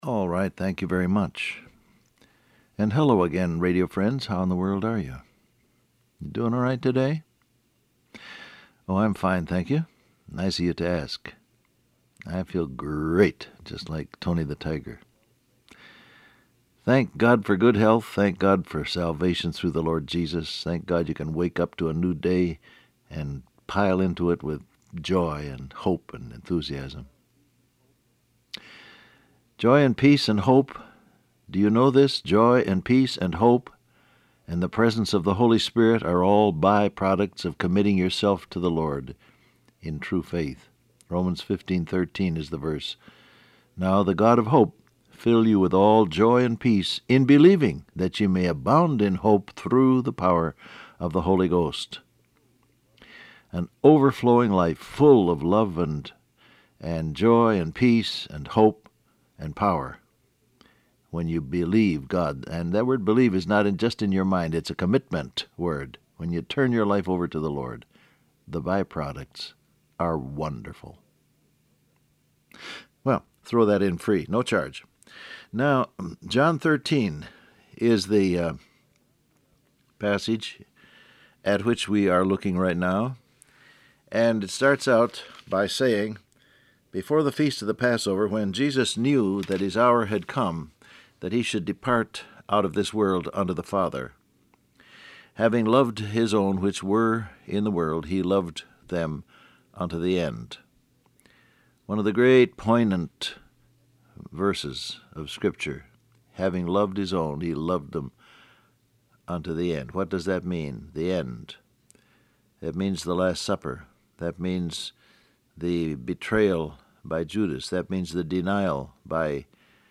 Download Audio Print Broadcast #6907 Scripture: John 13:1 Topics: Pray , Lamb Of God , Turn To God , Jesus Loves You Transcript Facebook Twitter WhatsApp Alright, thank you very much.